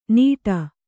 ♪ nīṭa